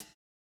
MOO Closed Hat 2.wav